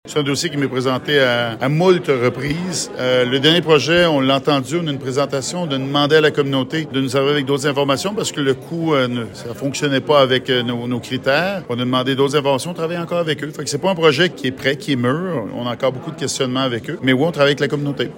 Le ministre responsable des Relations avec les Premières Nations et les Inuit, Ian Lafrenière, précise que le projet lui a été présenté à plusieurs reprises :